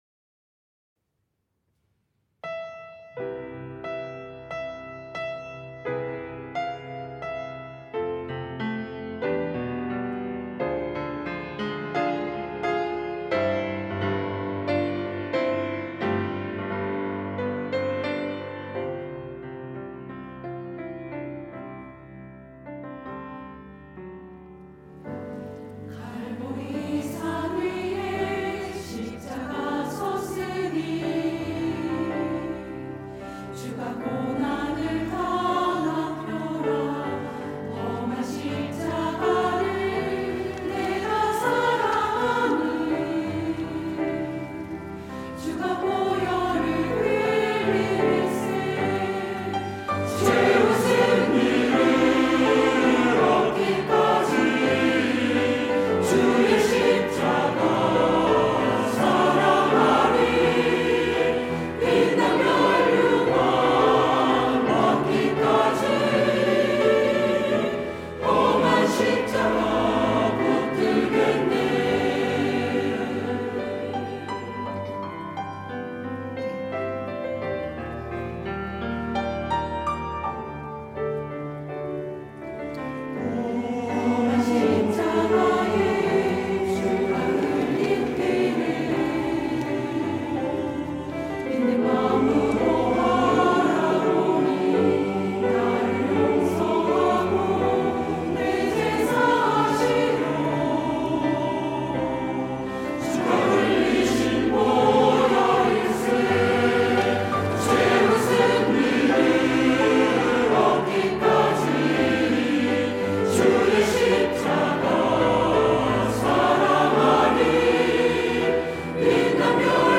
시온(주일1부) - 험한 십자가
찬양대